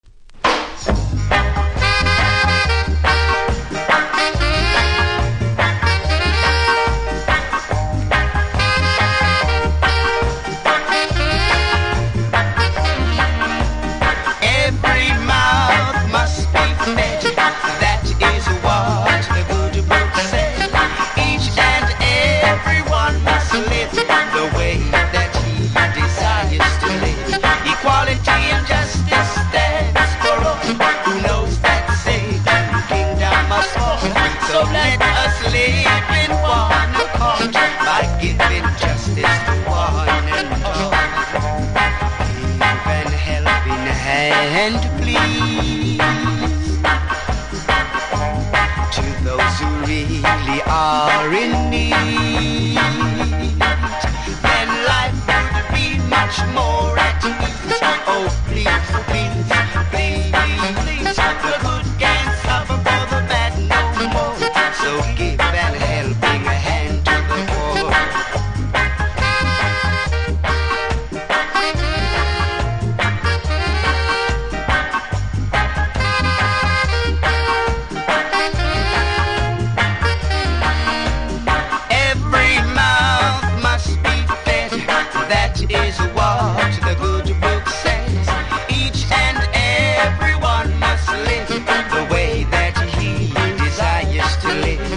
キズ多めですが音は良好なので試聴で確認下さい。